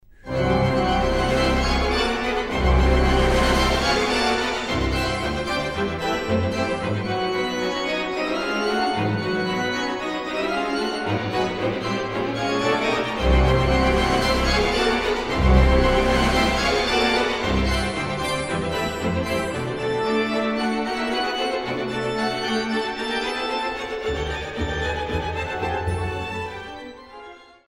Modéré